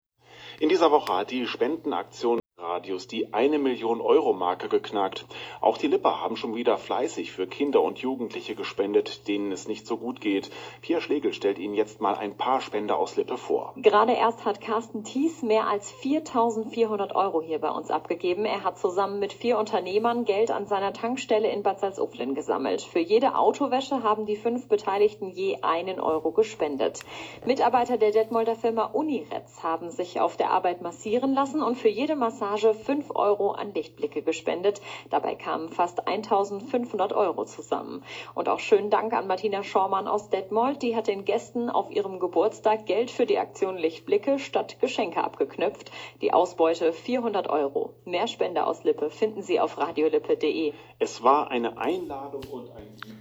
Lokalnachrichten am 22.12.2017 erwähnt.